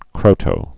(krōtō), Sir.